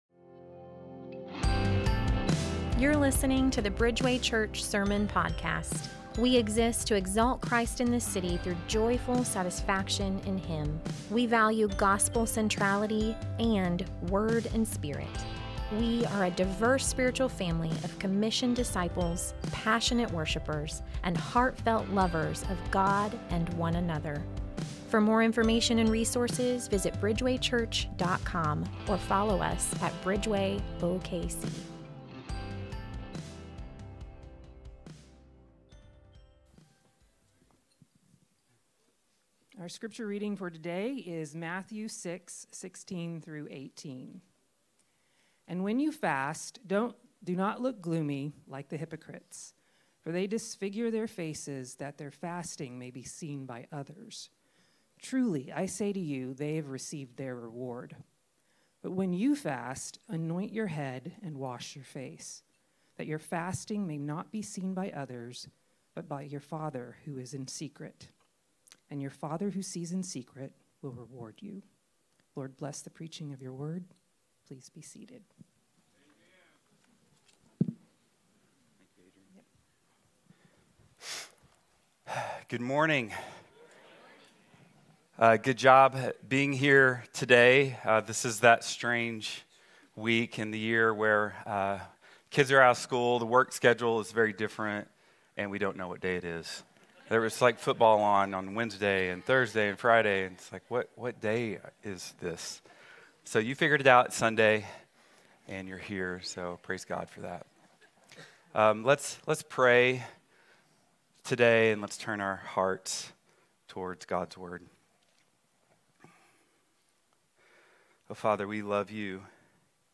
Bridgeway Church Sermons